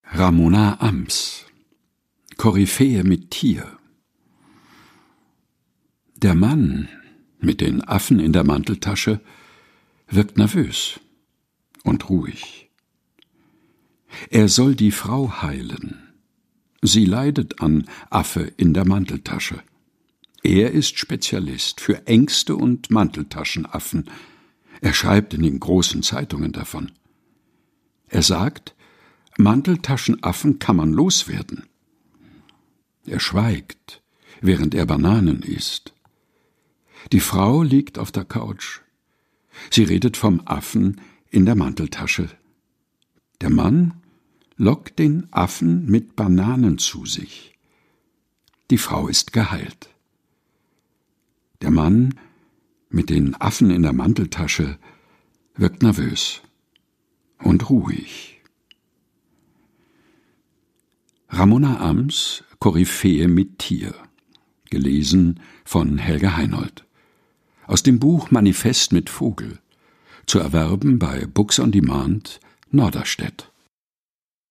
Wir danken der Autorin herzlich für die Genehmigung, in unserem Podcast aus ihrem Buch zu lesen.